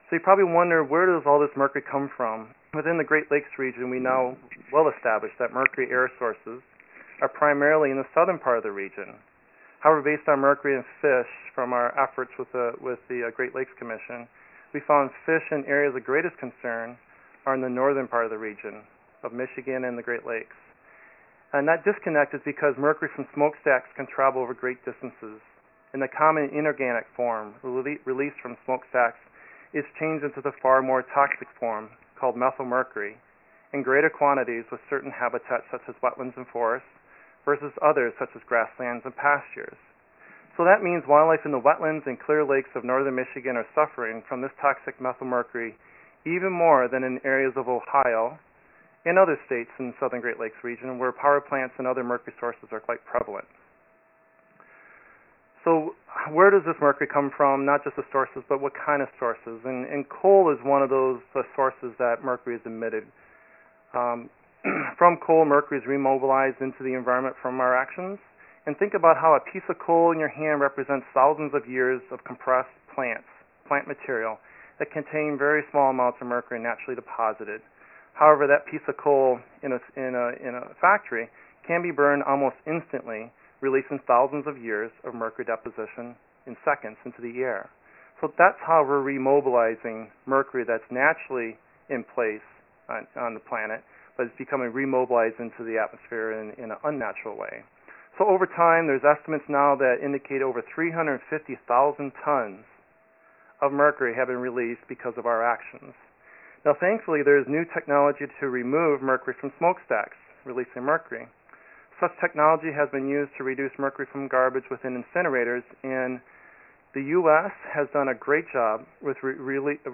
Mercury Town Hall Draws Michigan Conservationists